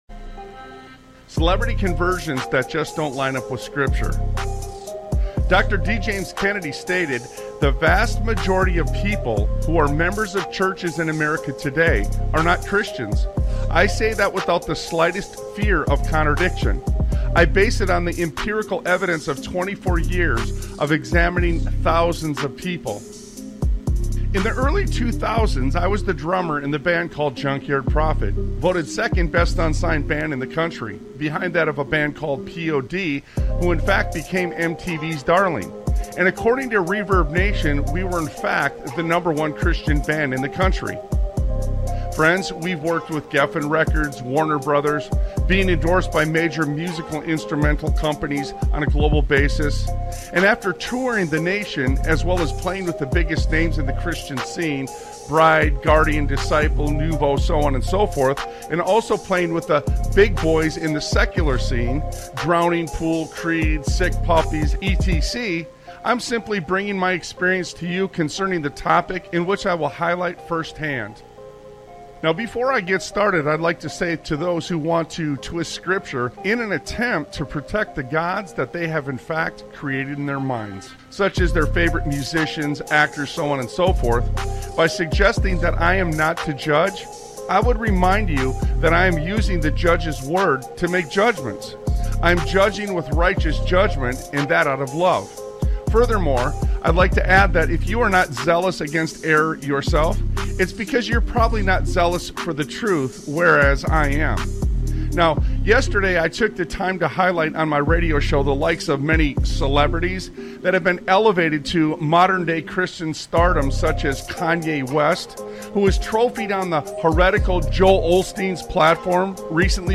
Talk Show Episode, Audio Podcast, Sons of Liberty Radio and INCOMING... on , show guests , about INCOMING..., categorized as Education,History,Military,News,Politics & Government,Religion,Christianity,Society and Culture,Theory & Conspiracy